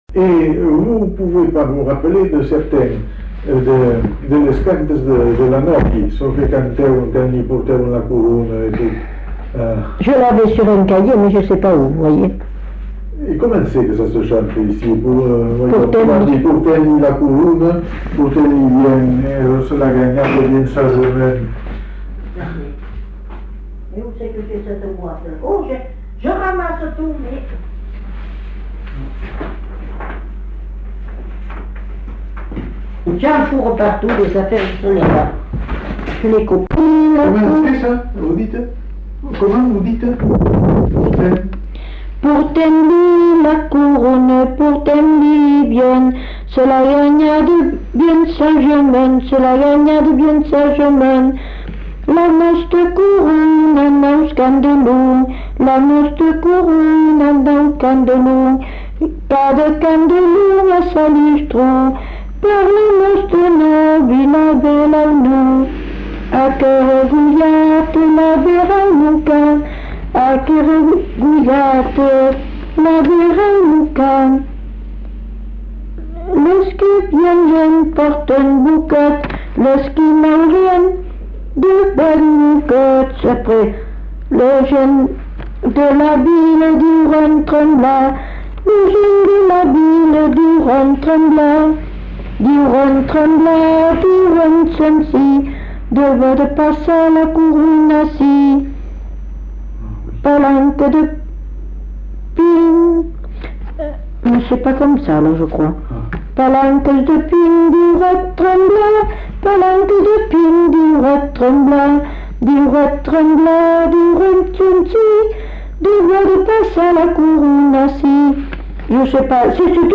Lieu : Belin-Beliet
Genre : chant
Effectif : 1
Type de voix : voix de femme
Production du son : chanté
Notes consultables : Lit les paroles dans un cahier.